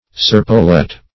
serpolet - definition of serpolet - synonyms, pronunciation, spelling from Free Dictionary Search Result for " serpolet" : The Collaborative International Dictionary of English v.0.48: Serpolet \Ser"po*let\, n. [F.]